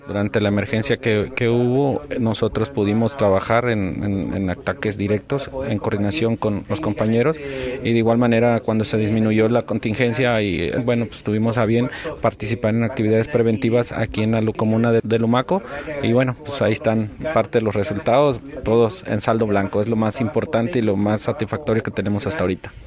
integrante del equipo que llegó a La Araucanía.